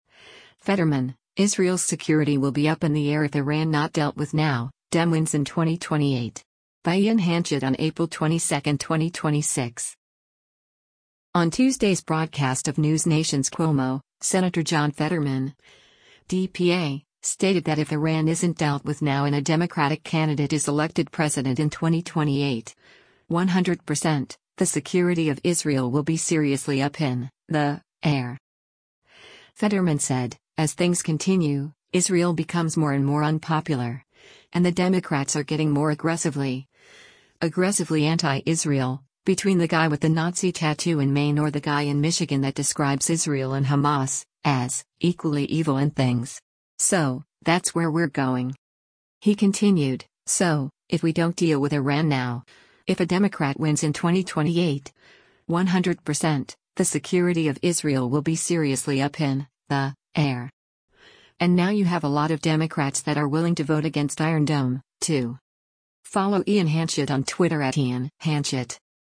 On Tuesday’s broadcast of NewsNation’s “Cuomo,” Sen. John Fetterman (D-PA) stated that if Iran isn’t dealt with now and a Democratic candidate is elected president in 2028, “100%, the security of Israel will be seriously up in [the] air.”